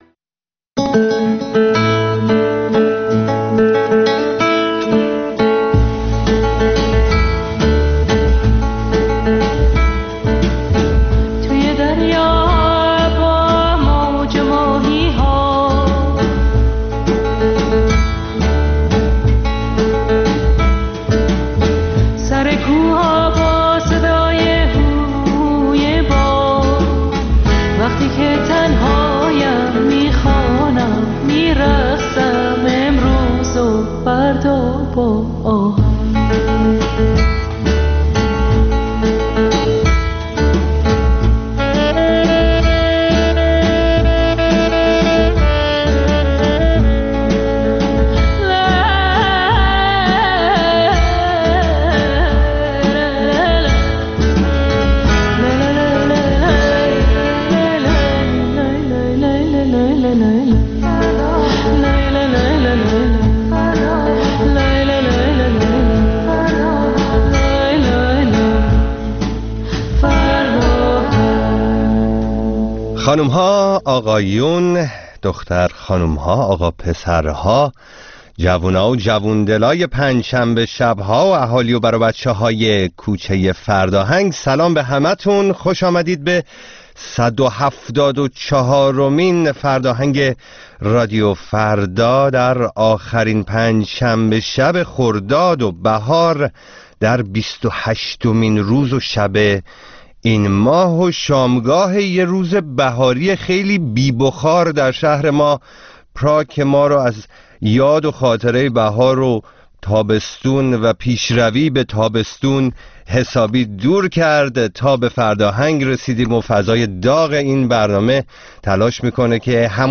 برنامه زنده بخش موسیقی رادیو فردا.